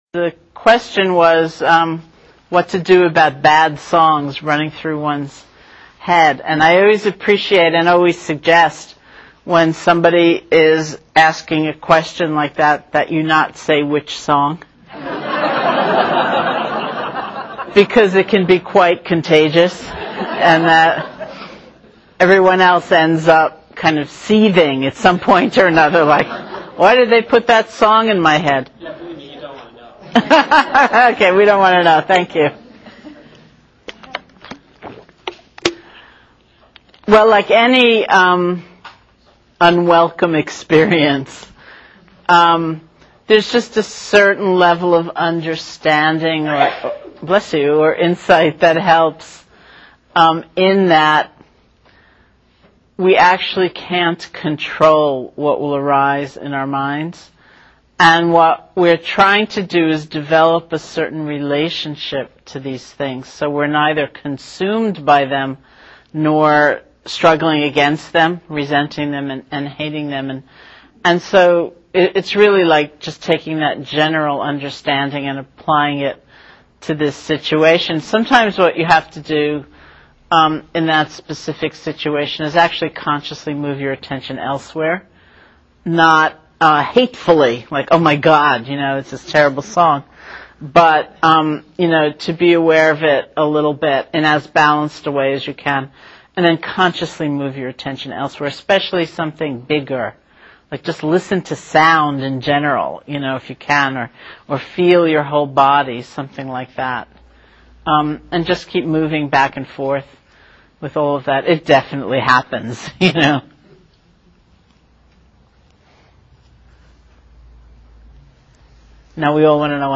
Q-and-A-with-Sharon-Salzberg.mp3